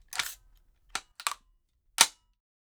Bullet In 2.wav